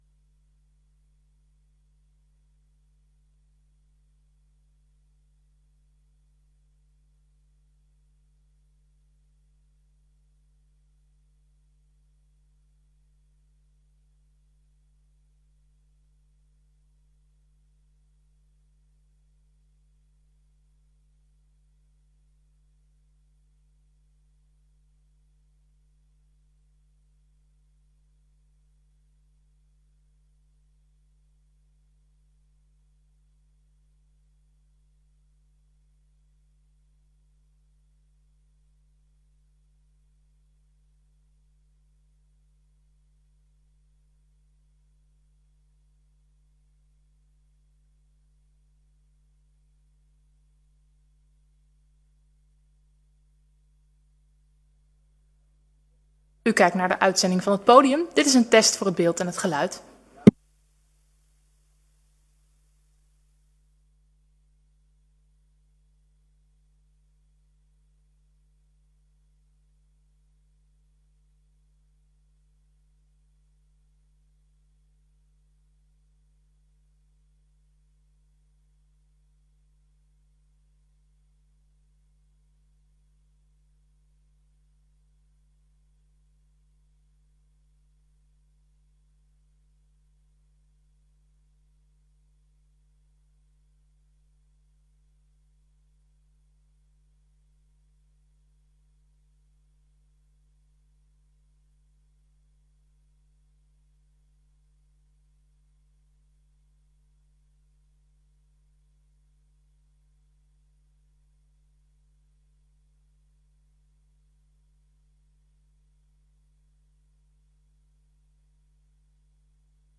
Gemeenteraadsleden mogen in deze tijd ook vragen aan u stellen.